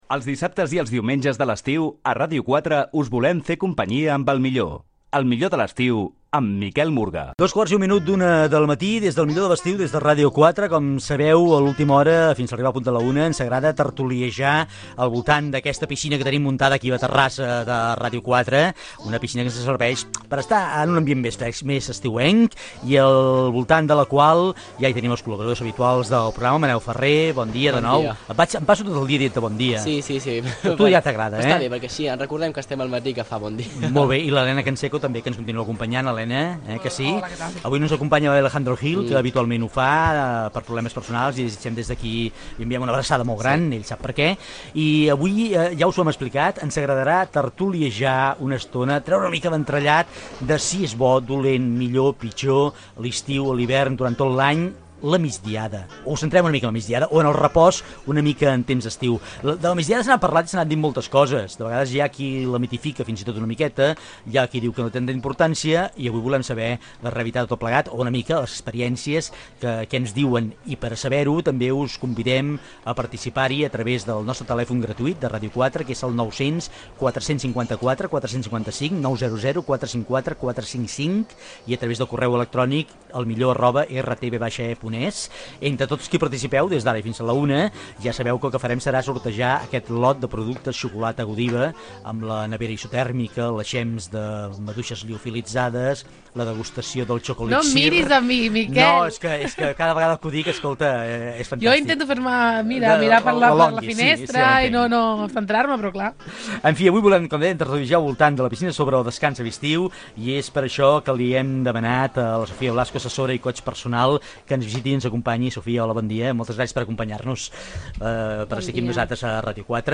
Al programa ‘El millor de cada casa’, a la secció ‘El millor de l’estiu’, de Ràdio 4, presentant algunes de les bondats de la migdiada.